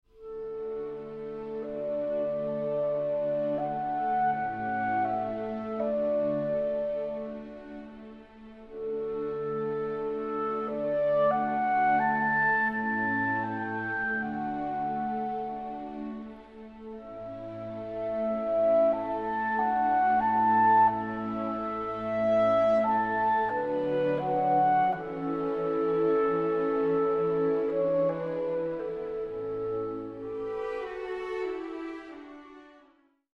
Works for Clarinet
Super Audio CD